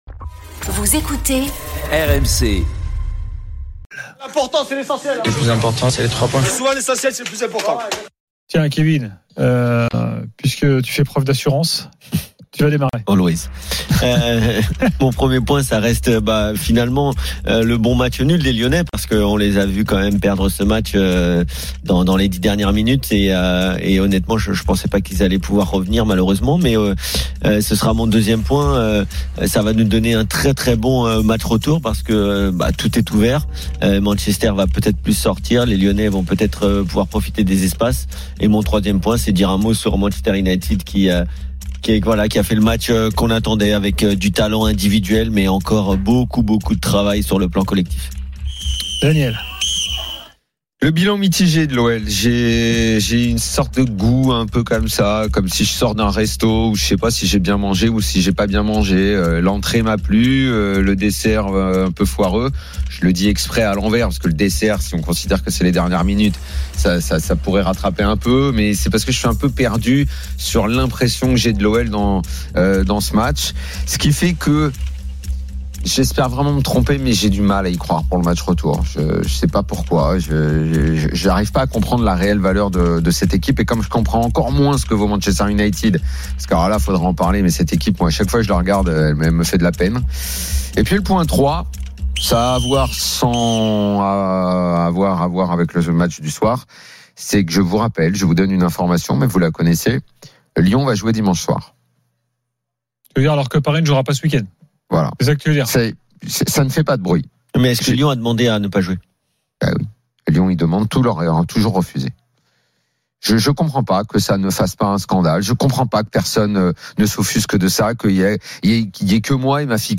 L’After foot, c’est LE show d’après-match et surtout la référence des fans de football depuis 19 ans !
Chaque jour, écoutez le Best-of de l'Afterfoot, sur RMC la radio du Sport !